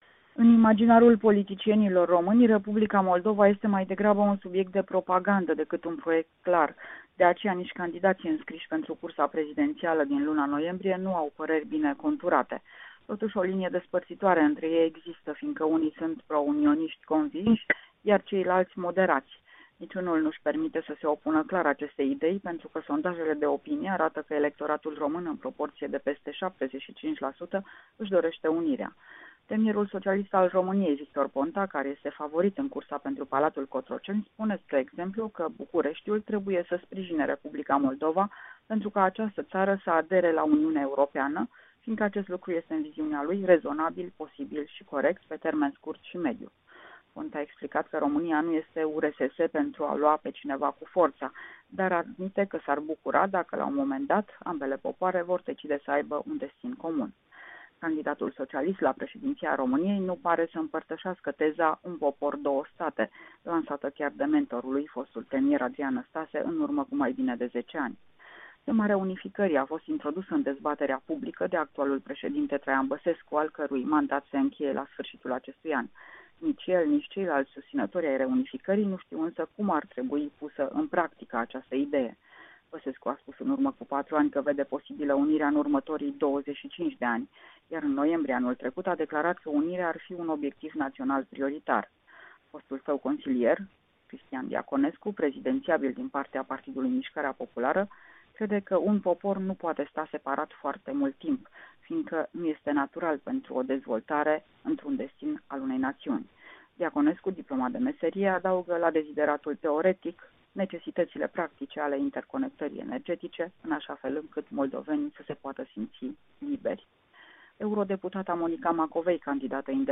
Corespondenţa zilei de la Bucureşti.